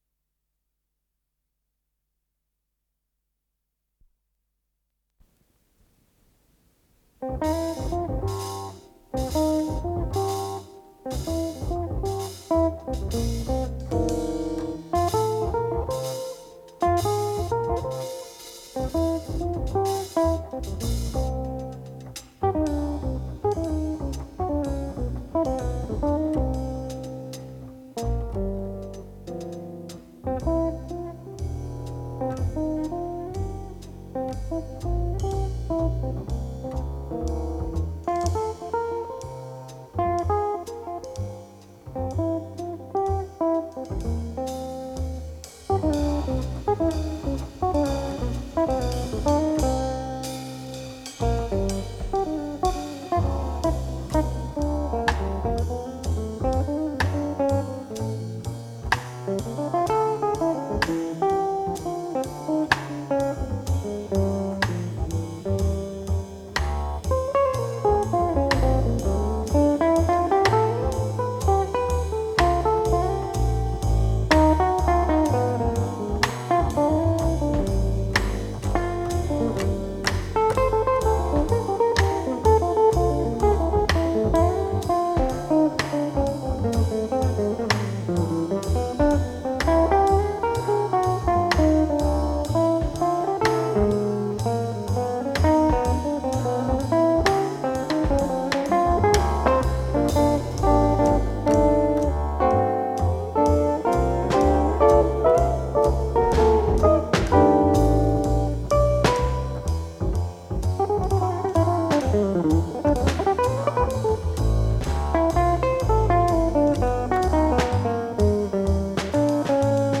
электрогитара
ударные
контрабас
Скорость ленты38 см/с
ВариантДубль моно